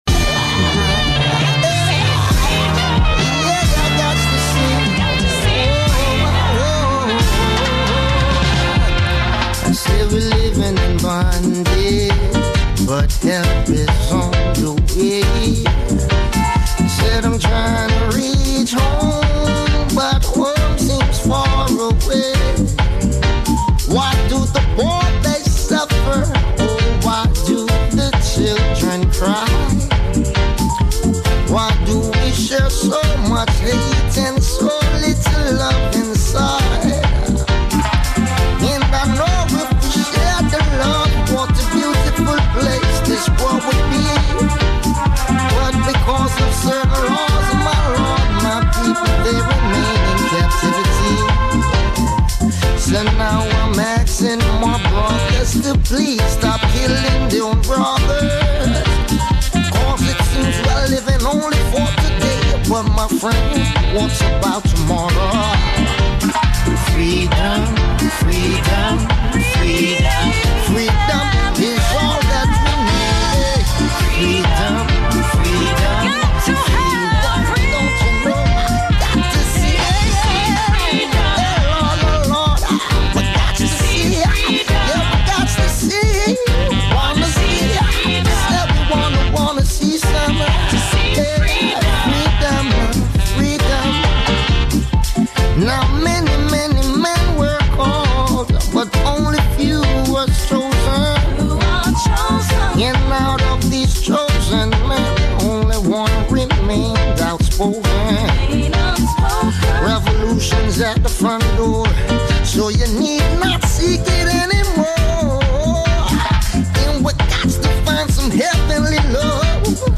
* Lion FreeCaency Radio Show *